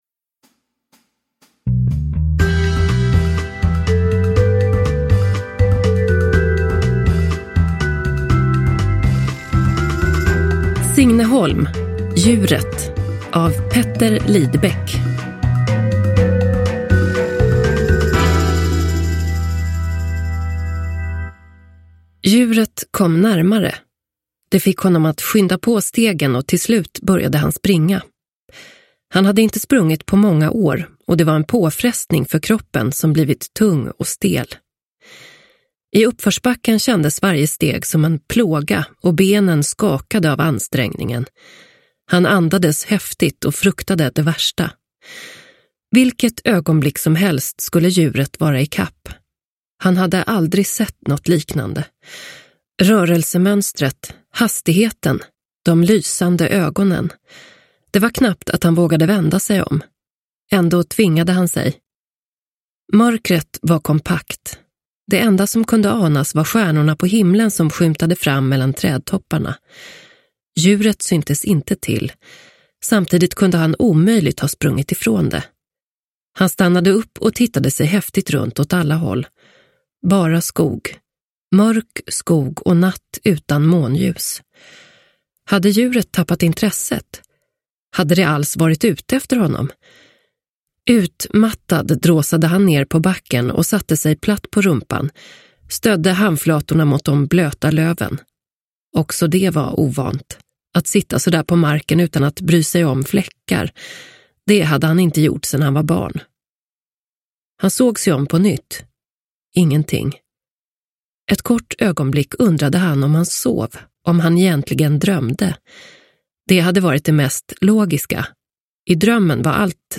Djuret – Ljudbok – Laddas ner